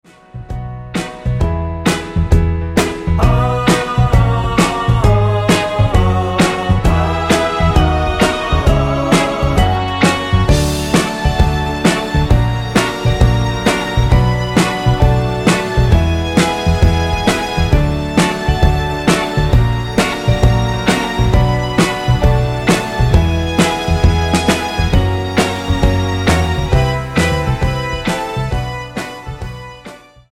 Tonart:B mit Chor